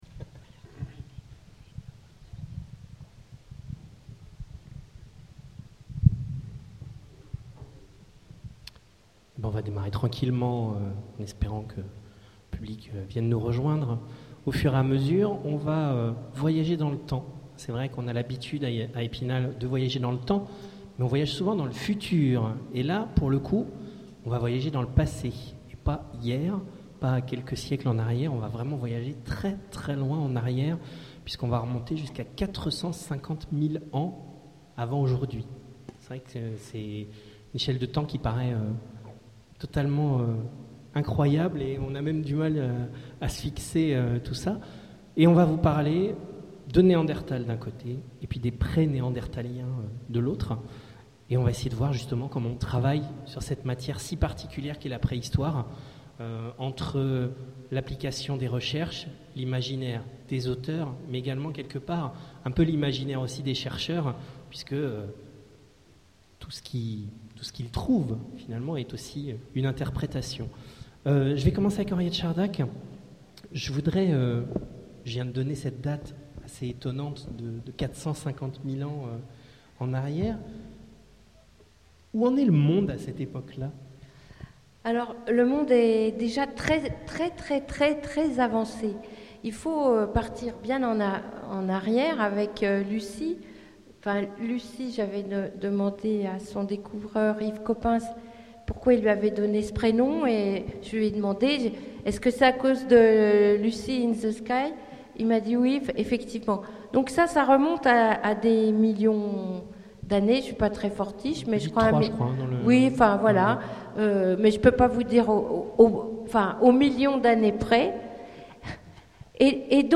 Imaginales 2014 : Conférence Romancer la préhistoire